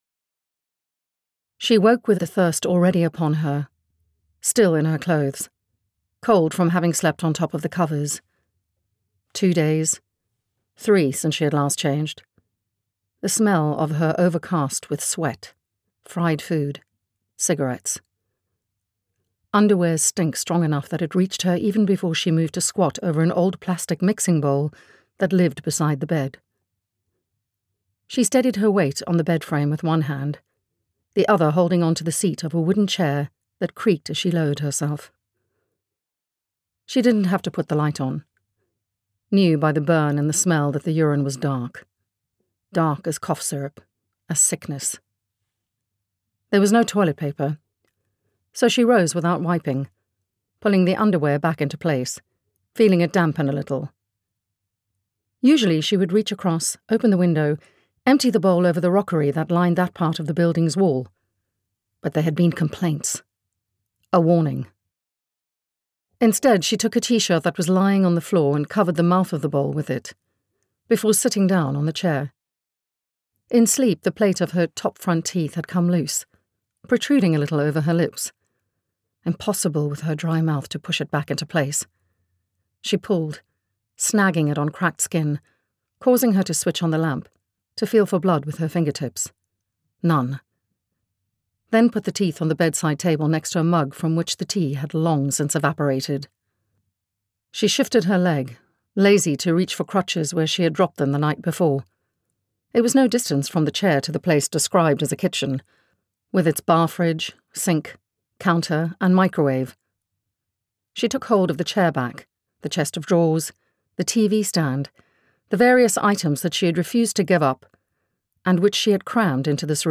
Audiobook sample
Crooked_Seeds_Clip_Chapter1.mp3